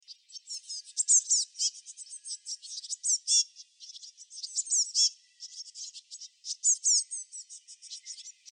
جلوه های صوتی
دانلود صدای پرنده 12 از ساعد نیوز با لینک مستقیم و کیفیت بالا